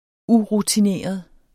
Udtale [ ˈuʁutiˌneˀʌð ]